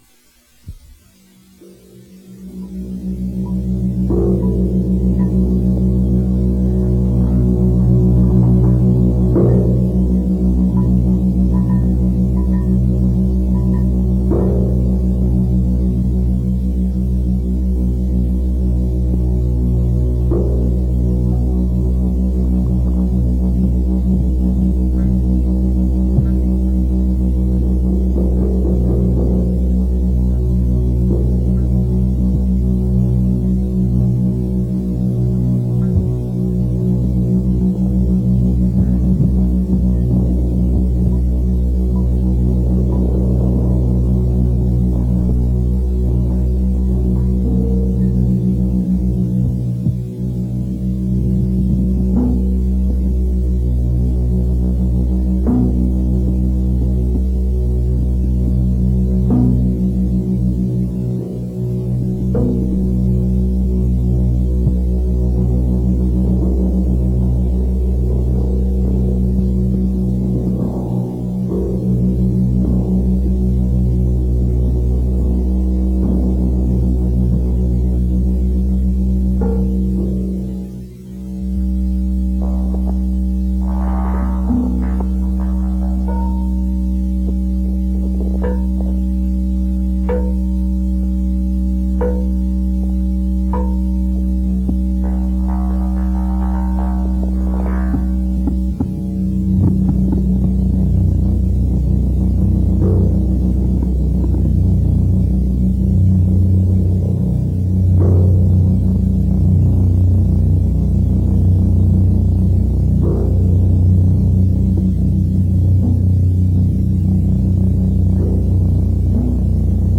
Sounds derived from a parking lot light.